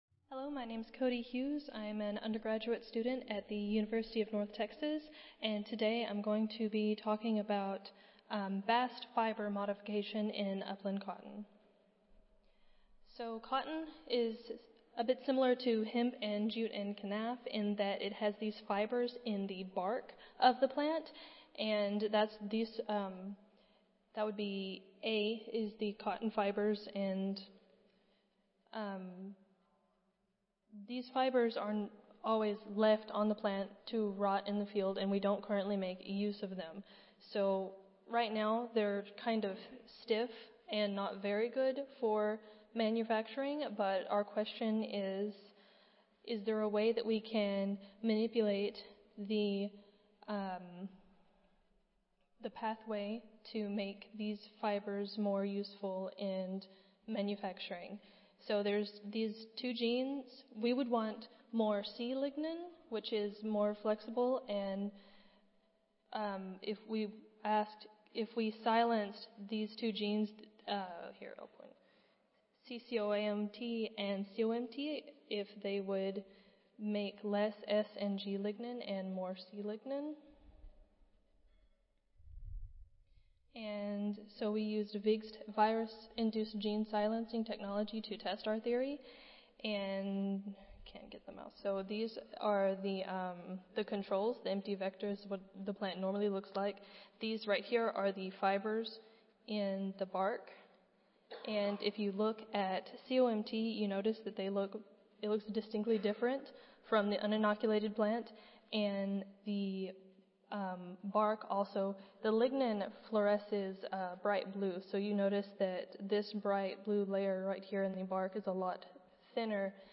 Cotton Improvement - Lightning Talk Student Competition
Audio File Recorded Presentation